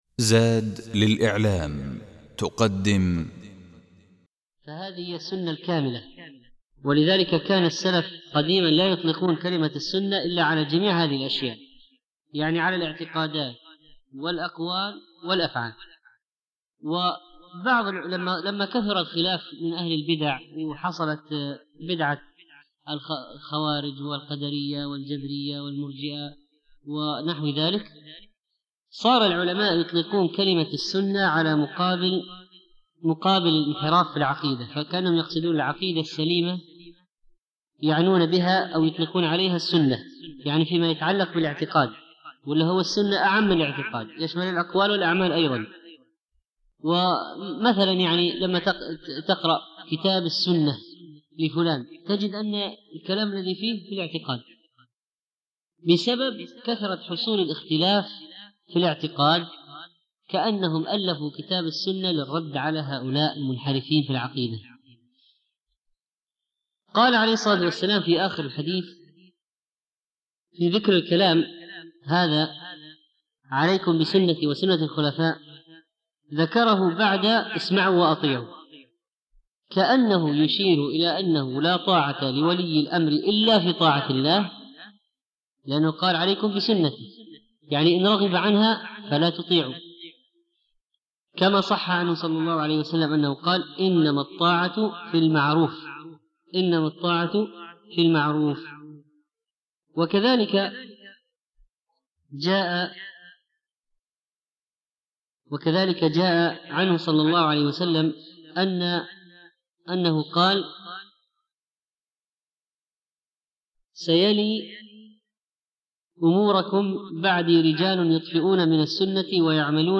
(31) الدرس الحادي والثلاثون